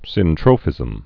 (sĭn-trōfĭzəm) also syn·tro·phy (sĭntrə-fē)